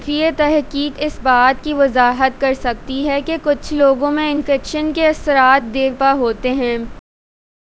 deepfake_detection_dataset_urdu / Spoofed_TTS /Speaker_10 /110.wav